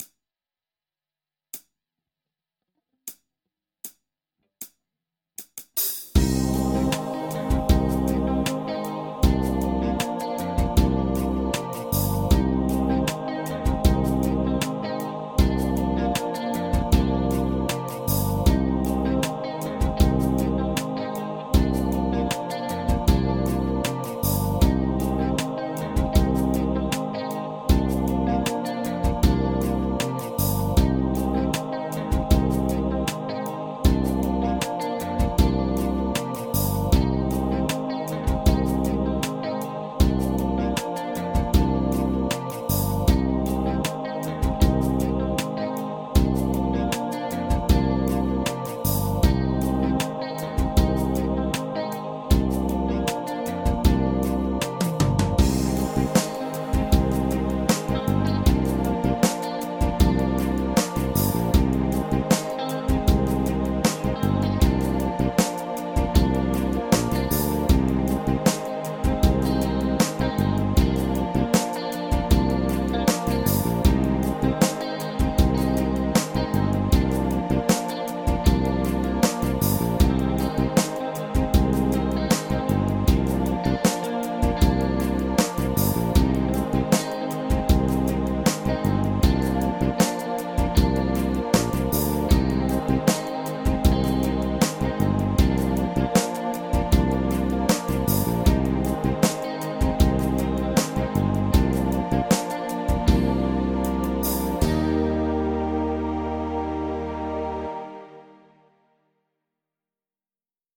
エオリアン・スケール ギタースケールハンドブック -島村楽器